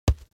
دانلود آهنگ تصادف 56 از افکت صوتی حمل و نقل
دانلود صدای تصادف 56 از ساعد نیوز با لینک مستقیم و کیفیت بالا